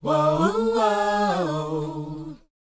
WHOA D#A U.wav